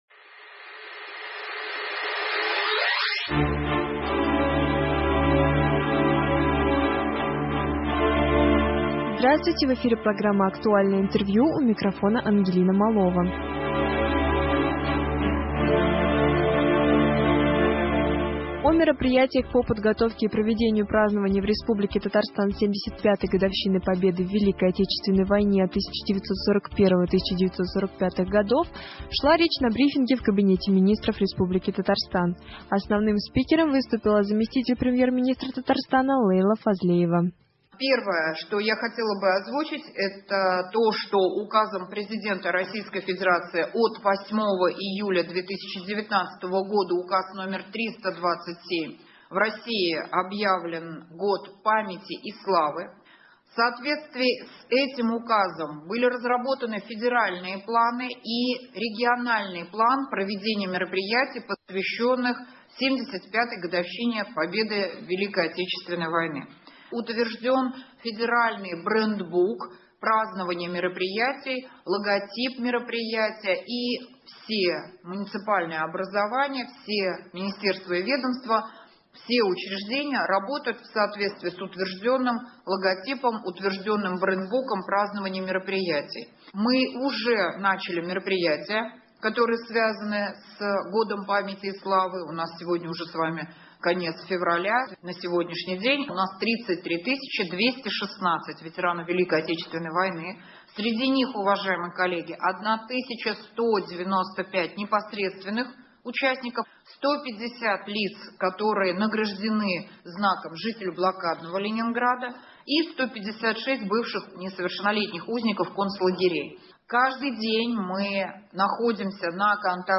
Актуально интервью. 26 февраля.